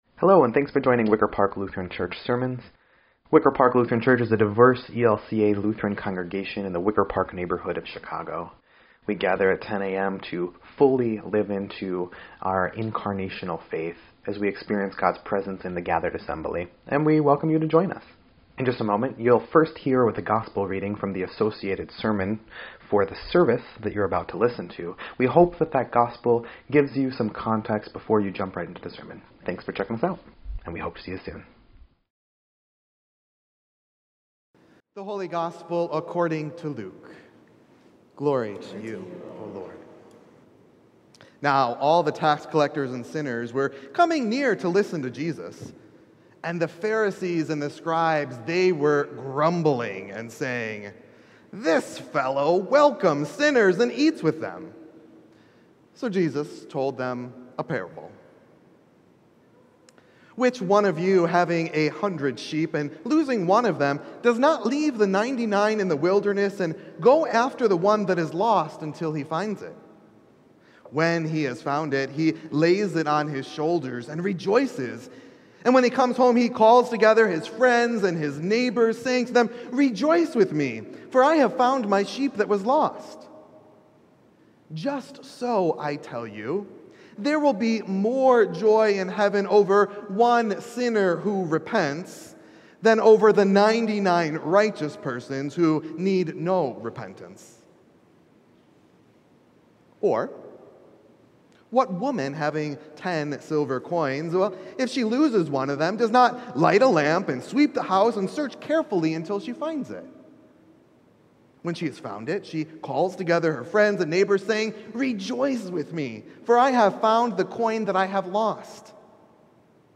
Fourteenth Sunday after Pentecost
9.11.22-Sermon_EDIT.mp3